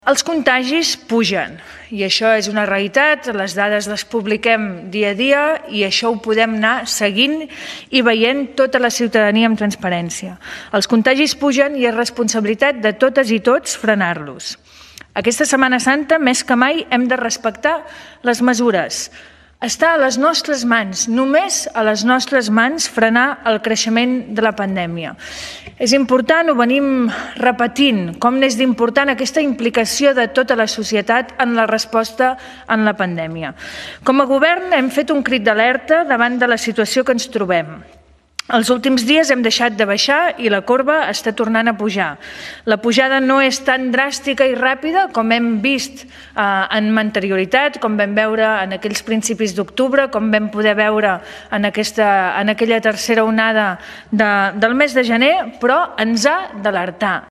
Declaracions d'Alba Vergès: